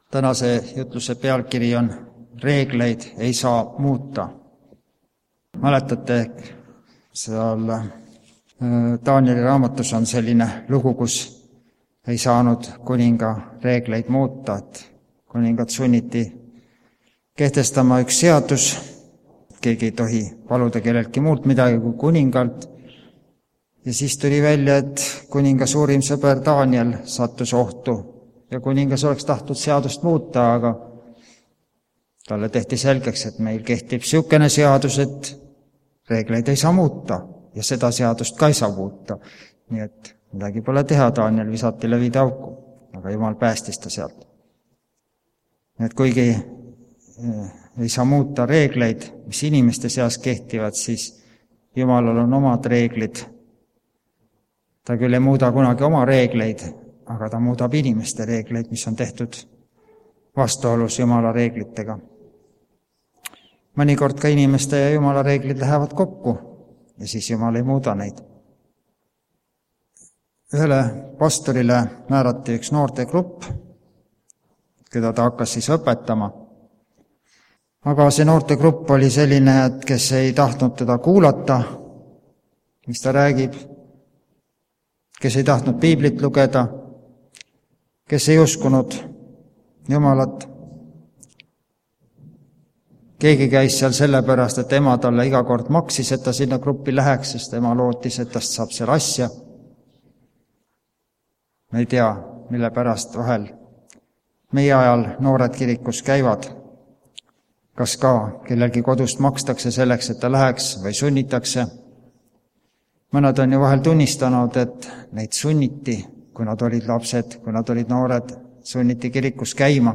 REEGLEID EI SAA MUUTA Koos saame ka laulda Tunned, et su julgus hakkab kaduma, usk ja lootus elumerel kõikuma, tahavad kui raskused sind rõhuda, lase päikest sisse sa!
Jutlused